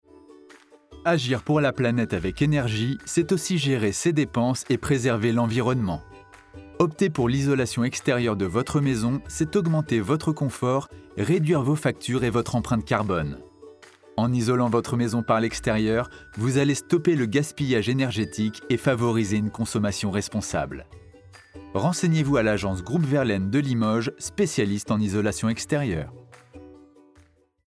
- Baryton